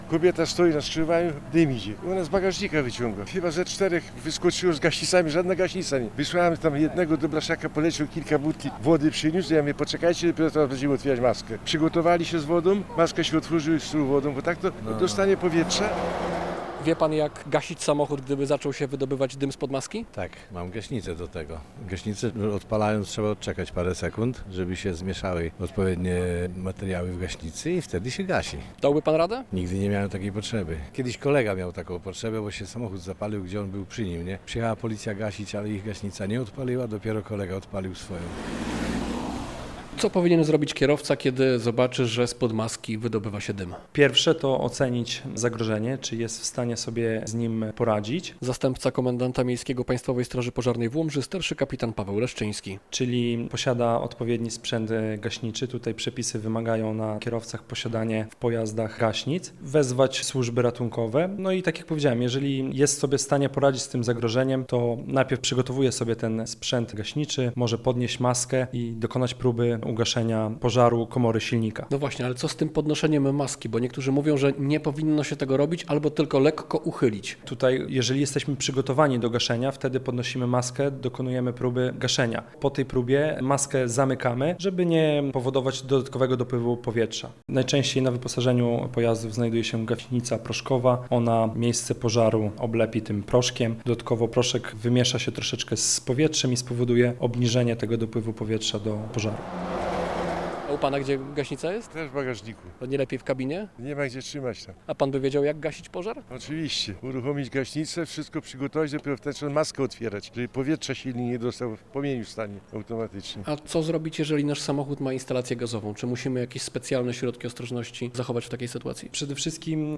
Kierowcy, z którym rozmawiał nasz dziennikarz, wiedzieli, że gaśnica jest obowiązkowym wyposażeniem samochodu.